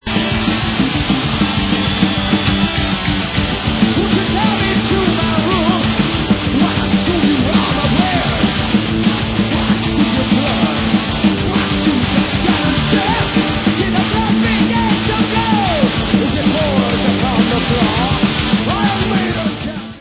sledovat novinky v oddělení Heavy Metal
Rock